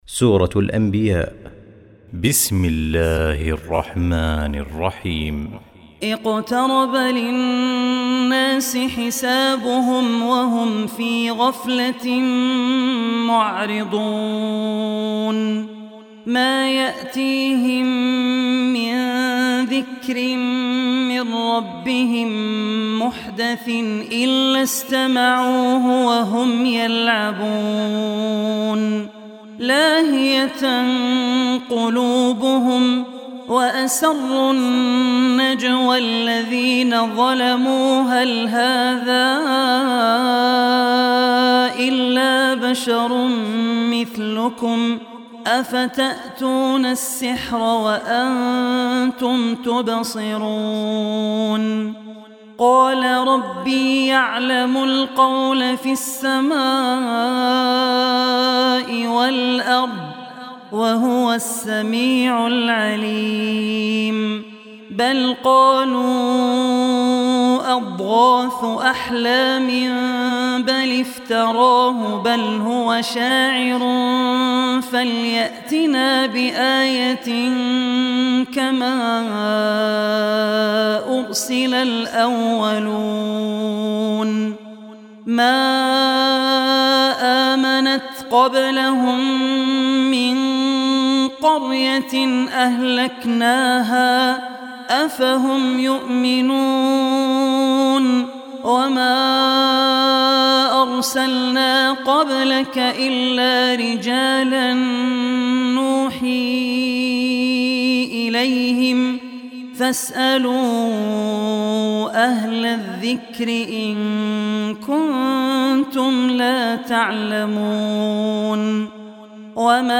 Surah Al Anbiya Recitation